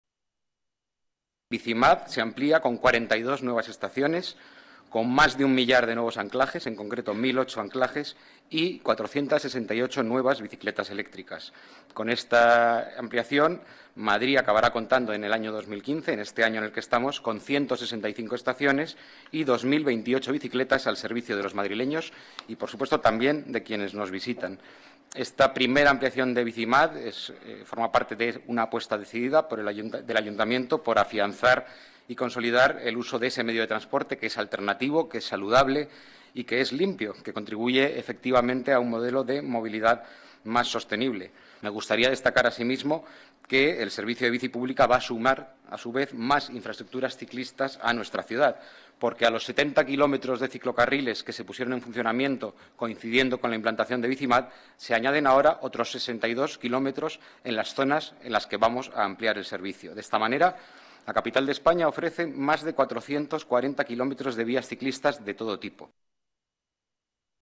Nueva ventana:Declaraciones del delegado del Área de Gobierno de Medio Ambiente y Movilidad, Diego Sanjuanbenito: Junta de Gobierno, ampliación BiciMAD